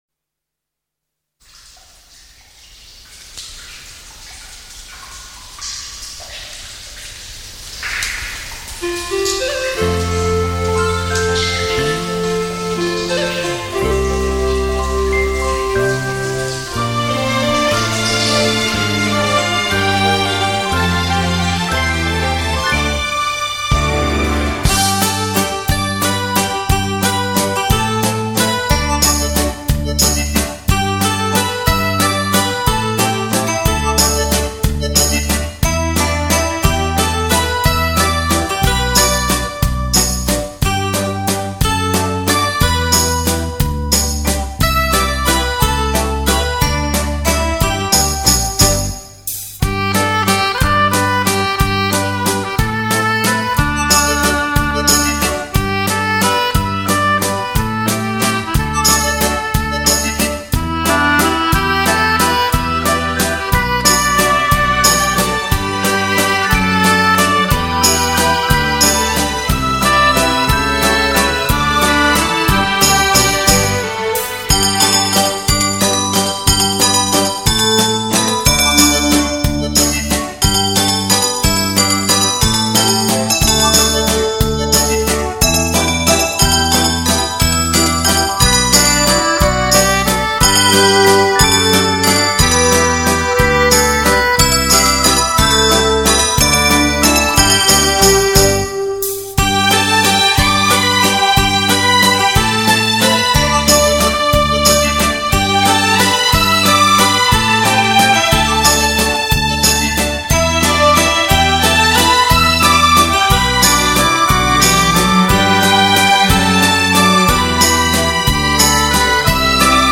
唱片类型： 交谊舞曲
负有代表性和广为传唱的优秀曲目，采用新颖的编曲手法，电声乐器与管弦乐队联合演奏，后
快三步
为低音质MP3